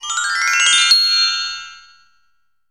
SFX_Win.wav